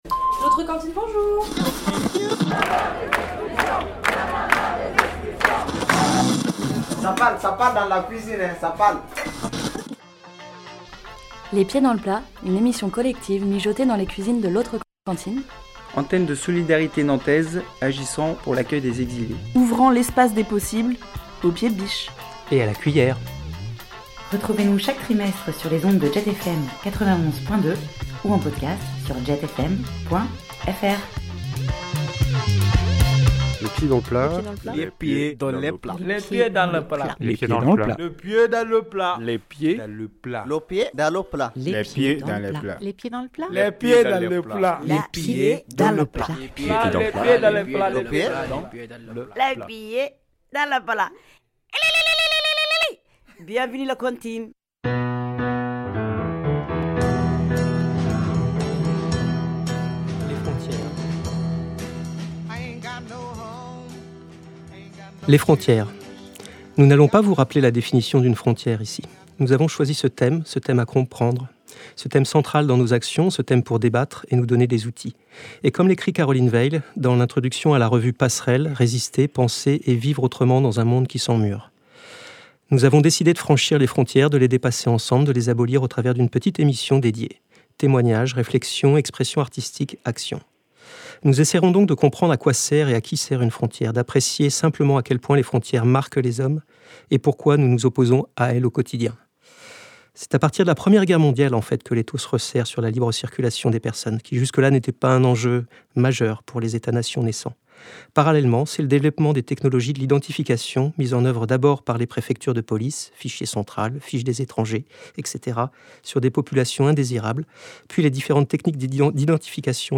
Une émission collective, mijotée dans les cuisines de l'Autre Cantine